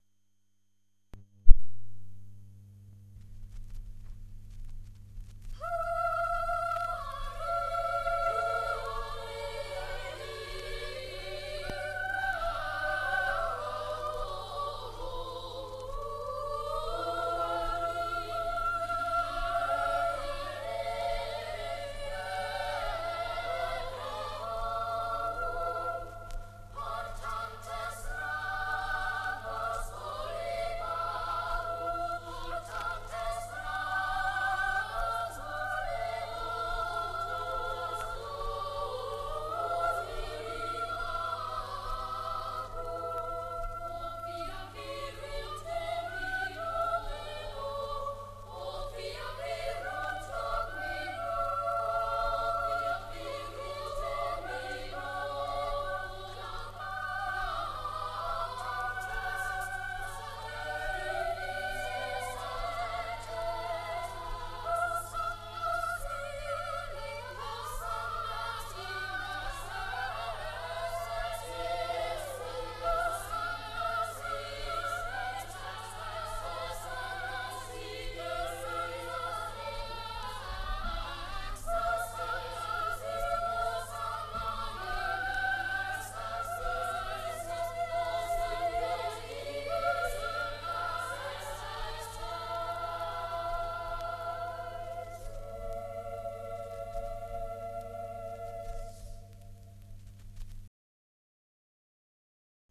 Palestrina, Pueri hebraeorum (mottetto).mp3